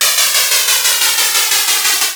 ihob/Assets/Extensions/RetroGamesSoundFX/Various/Various13.wav at master